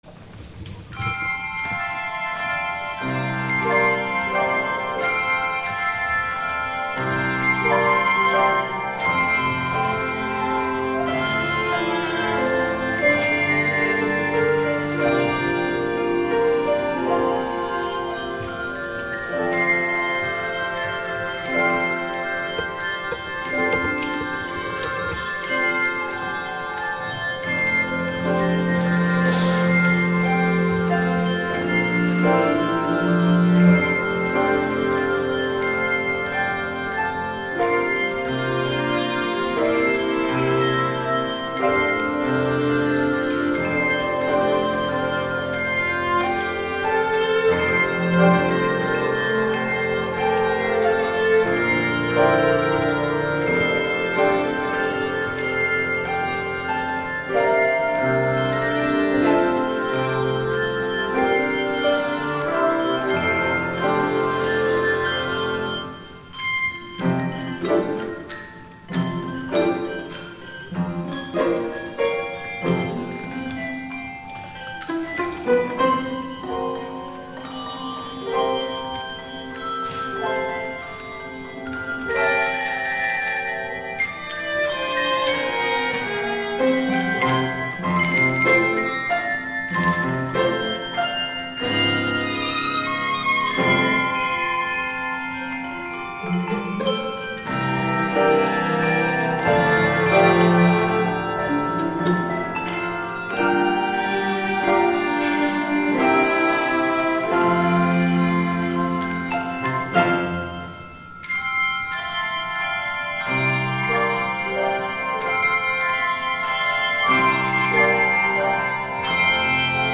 THE OFFERTORY
The Handbell Choir
flute
cello
organ
piano
violin